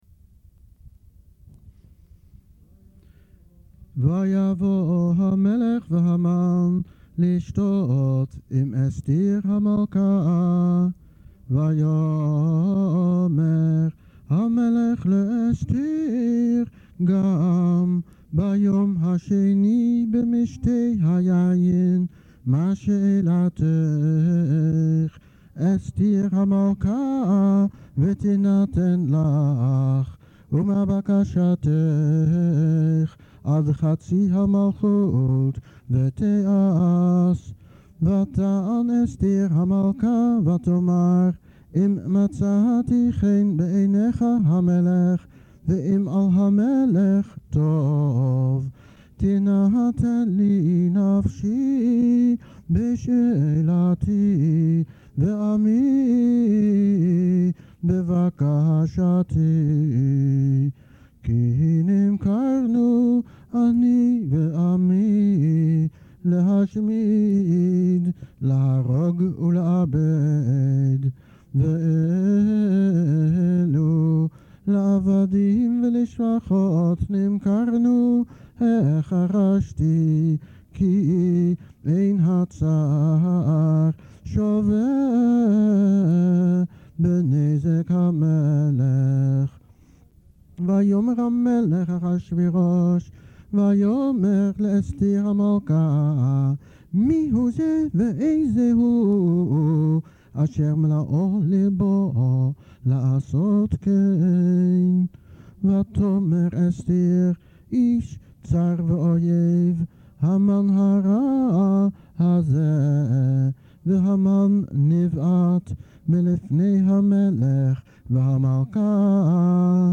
Voor alle enthousiaste megillelezers staat de Migillat Esther sinds vandaag weer online! De mp3’s zijn ingezongen